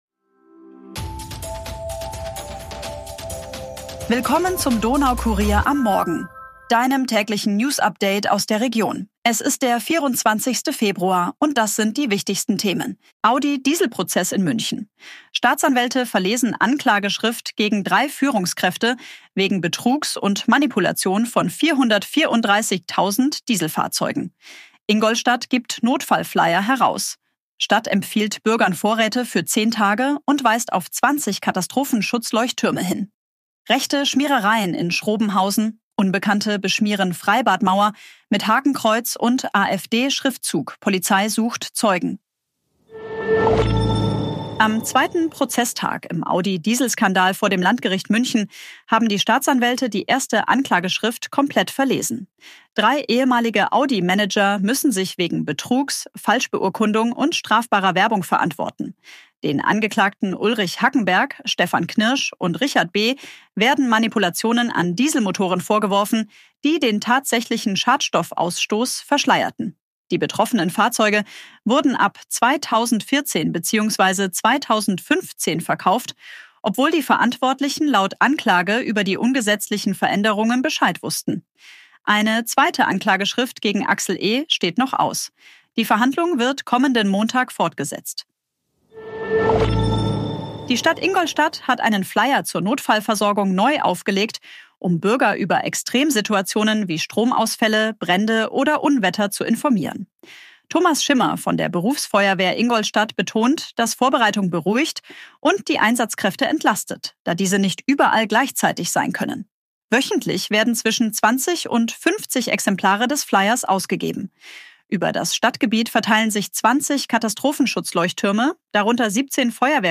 Tägliche Nachrichten aus deiner Region
Update wurde mit Unterstützung künstlicher Intelligenz auf Basis